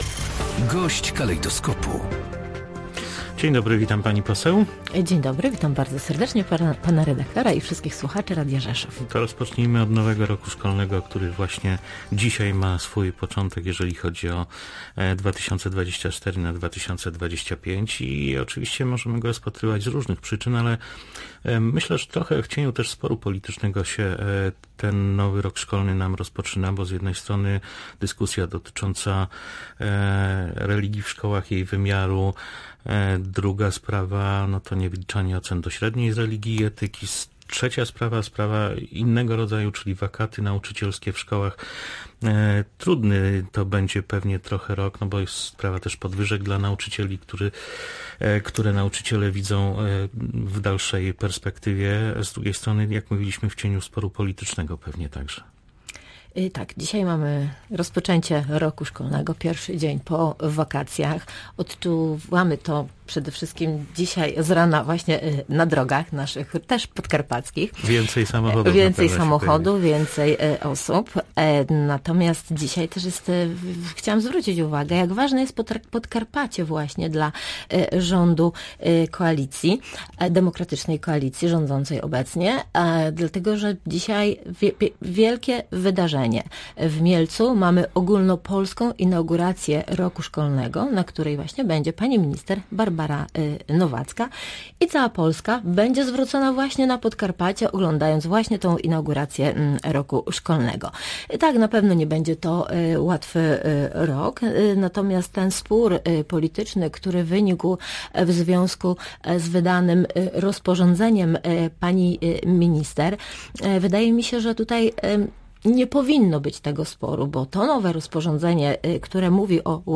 -Spór polityczny dotyczący lekcji religii nie powinien mieć miejsca, ponieważ możliwość łączenia klas jest praktykowana także w przypadku innych przedmiotów szkolnych – podkreślała na antenie Polskiego Radia Rzeszów Joanna Frydrych, posłanka KO.